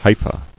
[hy·pha]